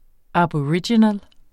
Udtale [ abʌˈɹidjinəl ]